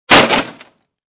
brokenCrate.wav